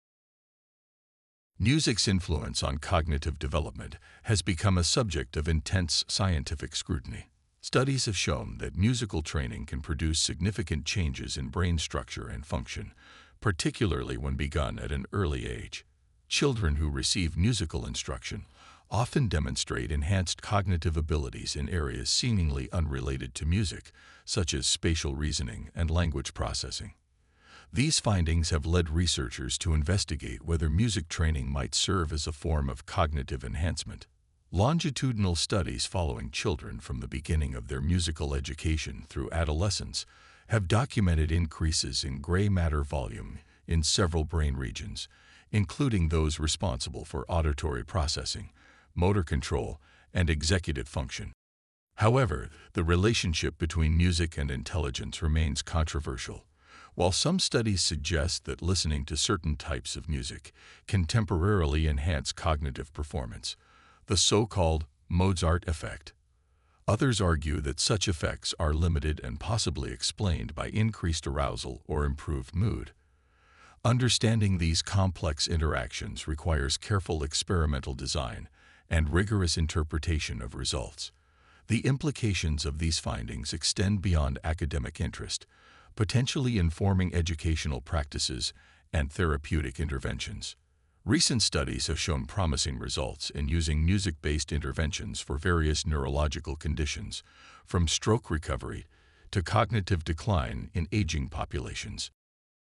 【朗読用音声】B